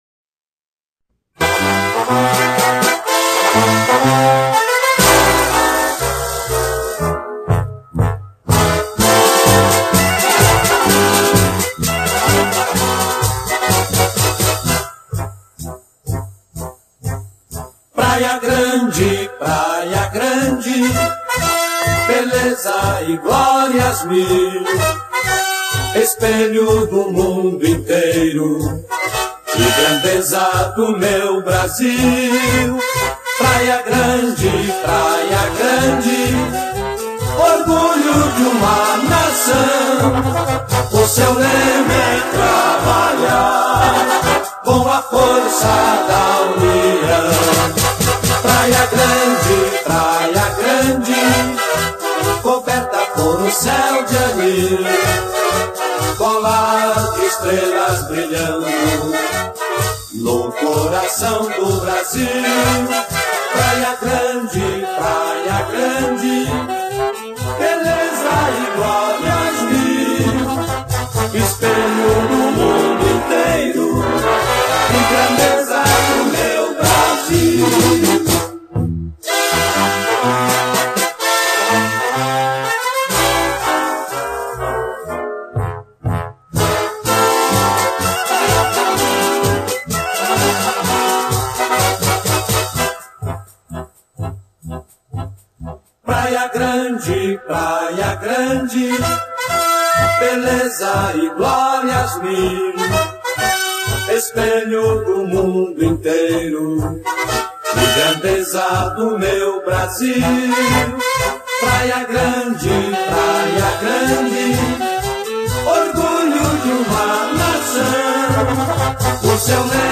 HINO (Letra e música: Oscar Gomes Cardim)